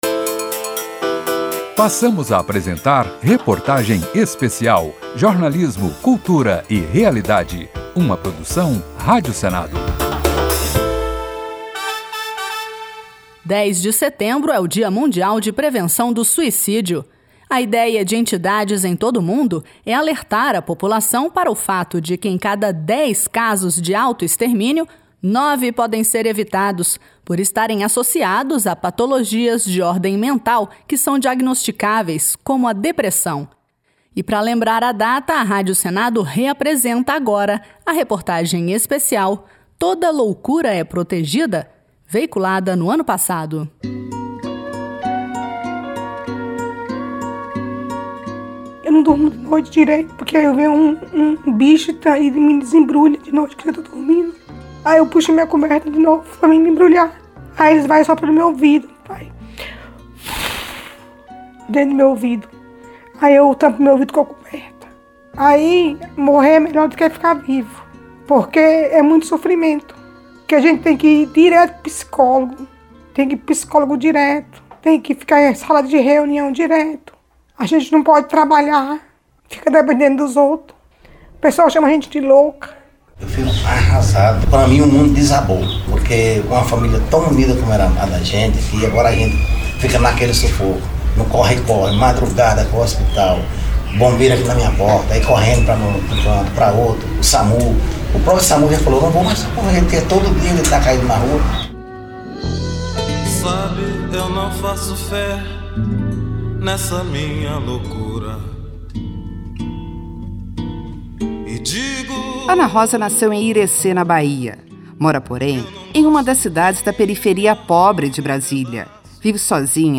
Especialistas em saúde mental, além de pacientes e familiares de pessoas que sofrem com esse tipo de transtorno foram ouvidos.